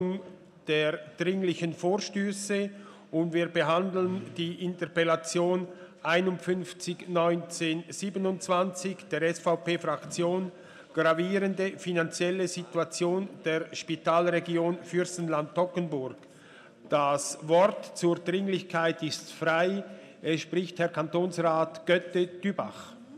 Ratsvizepräsident: Die Regierung bestreitet die Dringlichkeit.
Session des Kantonsrates vom 23. und 24. April 2019